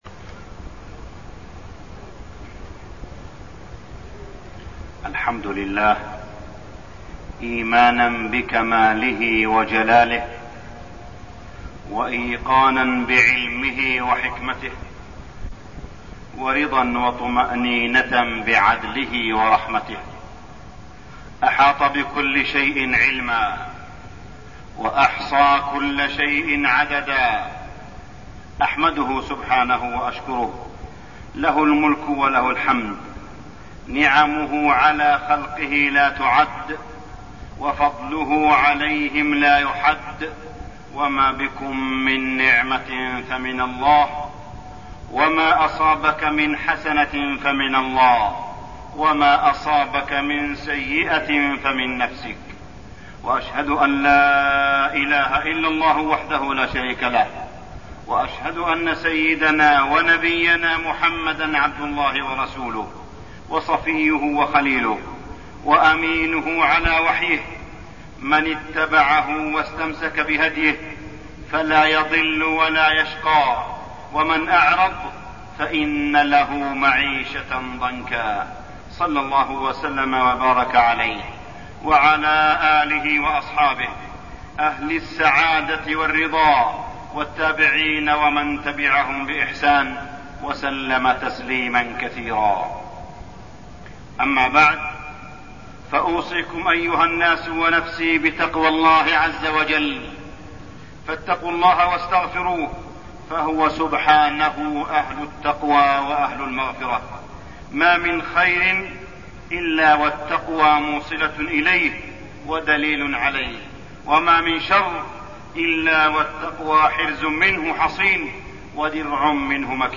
تاريخ النشر ٢٦ جمادى الأولى ١٤١٦ هـ المكان: المسجد الحرام الشيخ: معالي الشيخ أ.د. صالح بن عبدالله بن حميد معالي الشيخ أ.د. صالح بن عبدالله بن حميد مفهوم السعادة